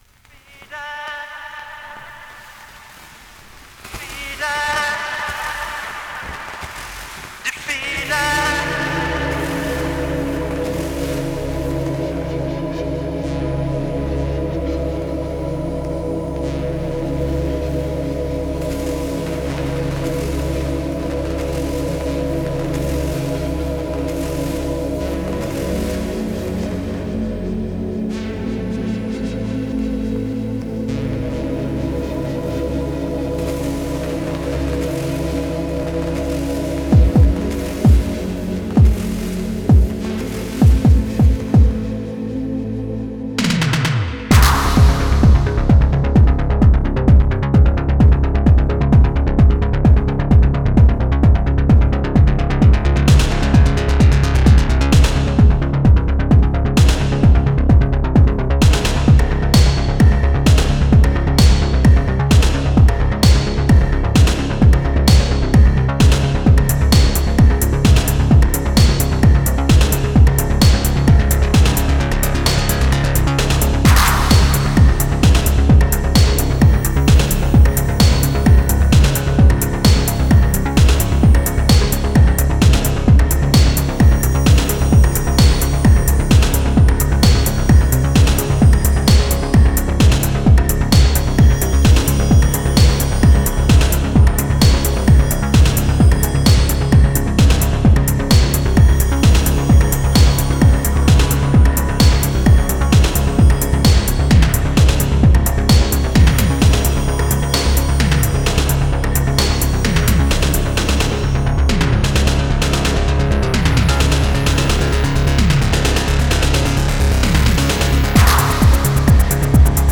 Genre: Electronic SpaceSynth.